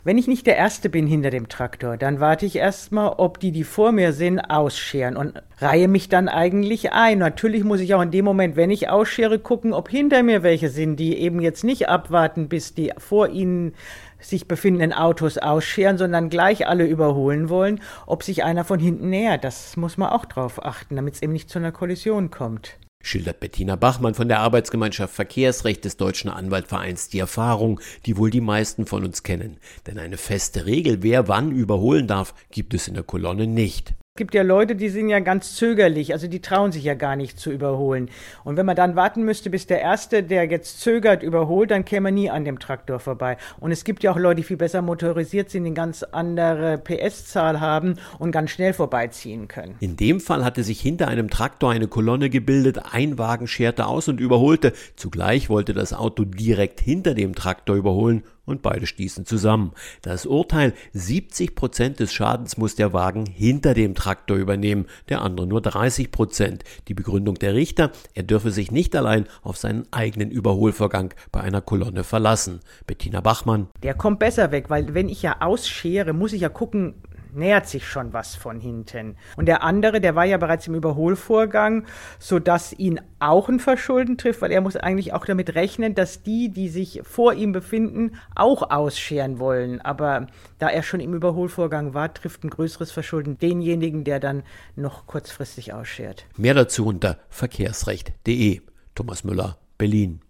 Magazin: Kolonne überholt – wer muss den Unfallschaden bezahlen?